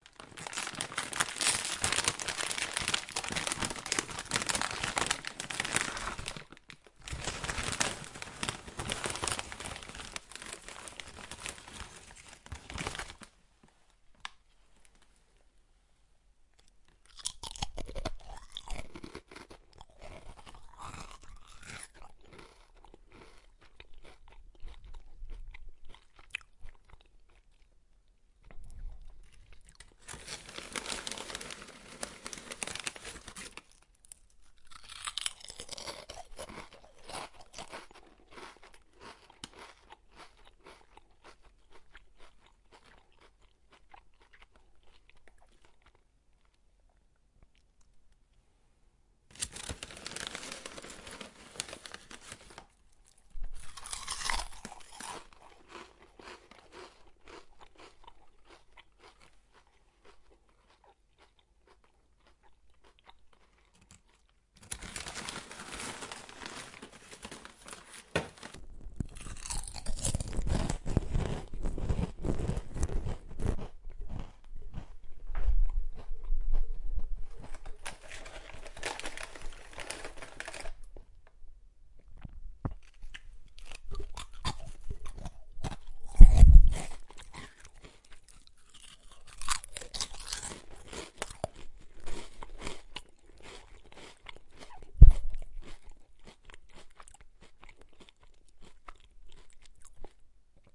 吃嚼薯条
描述：吃嚼薯条
标签： 薯片 薯条
声道立体声